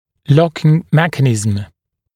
[‘lɔkɪŋ ‘mekənɪzəm][‘локин ‘мэкэнизэм]запирающий механизм